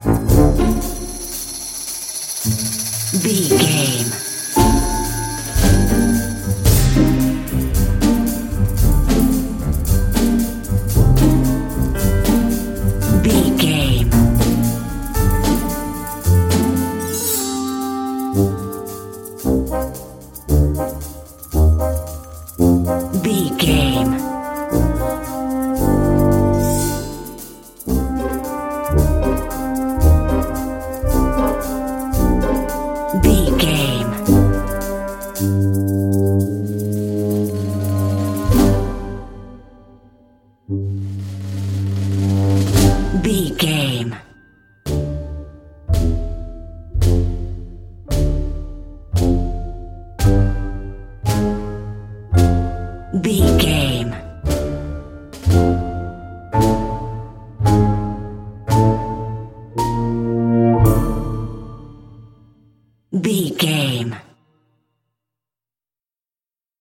Aeolian/Minor
orchestra
percussion
strings
horns
piano
silly
circus
goofy
comical
cheerful
perky
Light hearted
quirky